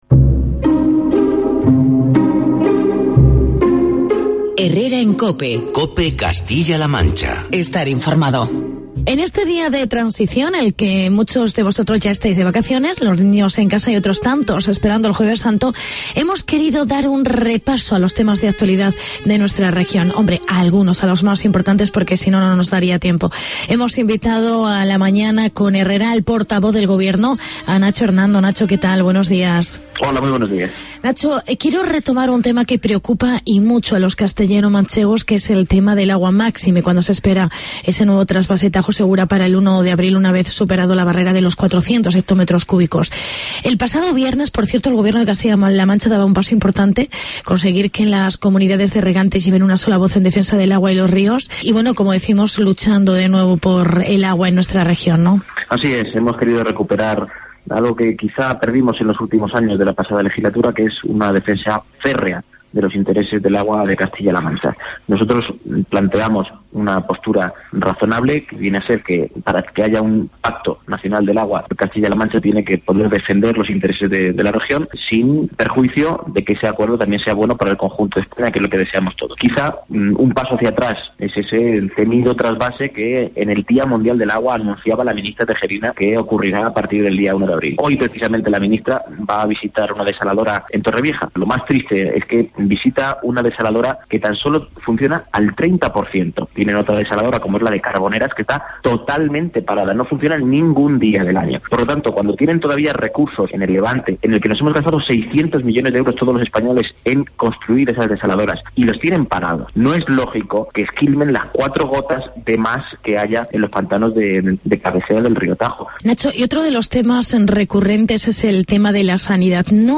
Entrevista con Nacho Hernando